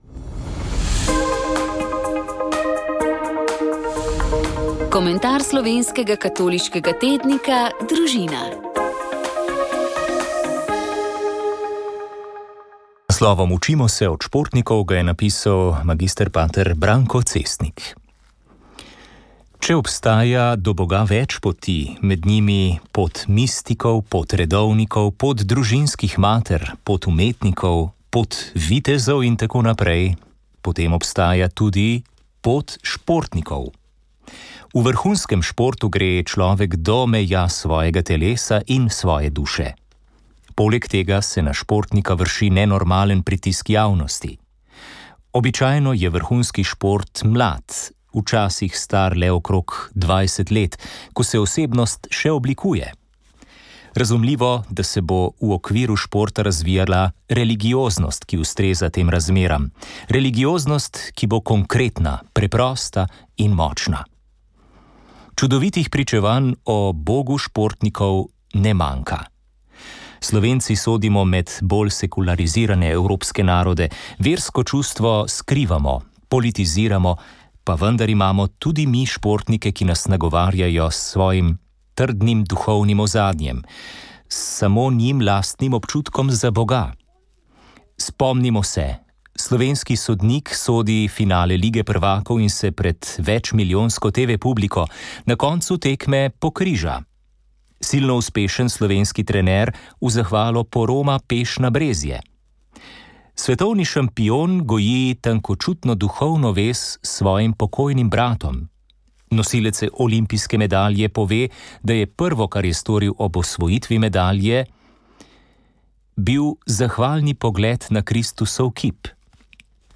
komentar